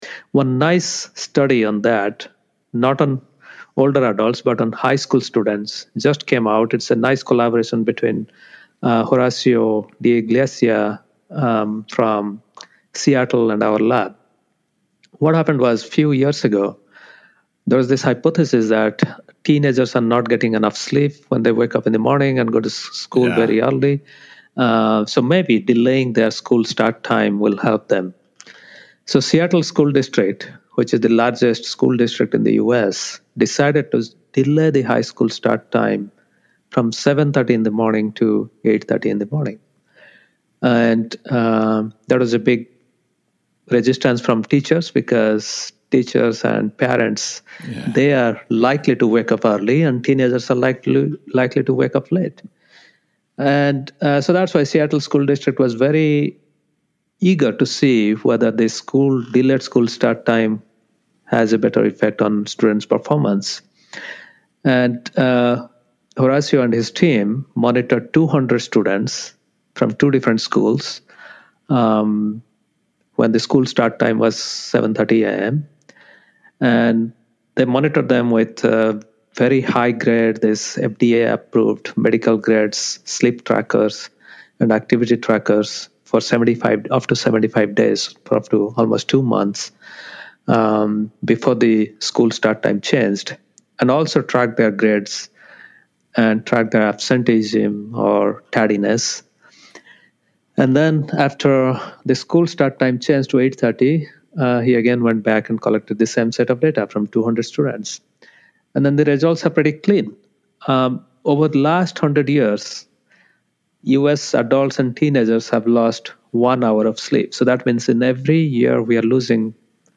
To start the analysis, please listen to this ~5min discussion from recent Bulletproof Radio episode1: